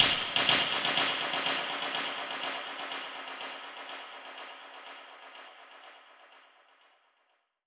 Index of /musicradar/dub-percussion-samples/125bpm
DPFX_PercHit_C_125-07.wav